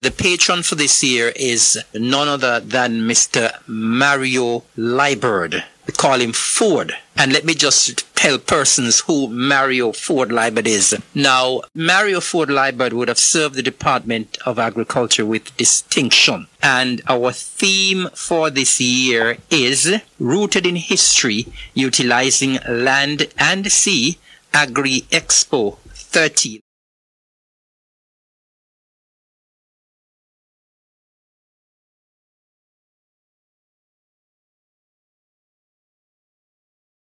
The two-day event will showcase Nevis’ entire Agriculture Sector. Minister Evelyn revealed the patron’s name and theme for the Expo: